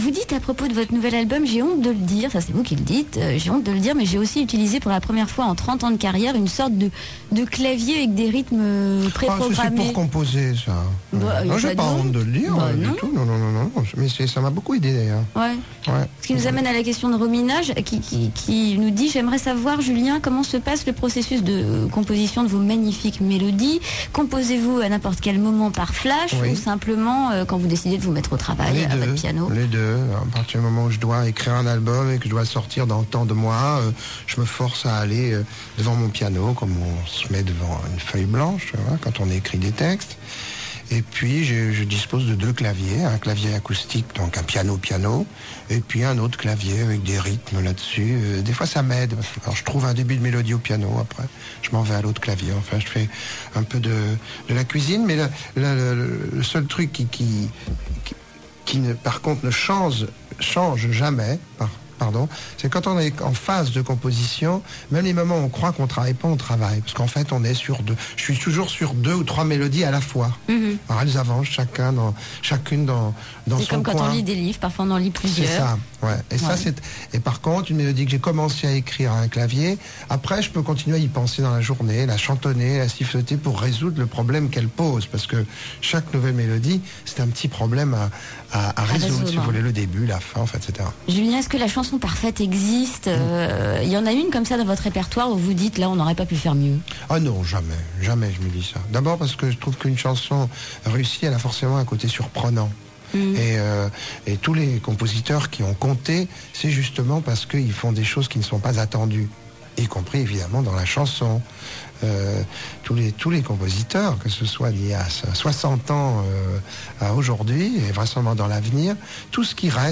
MFM Interview 23 mai 2006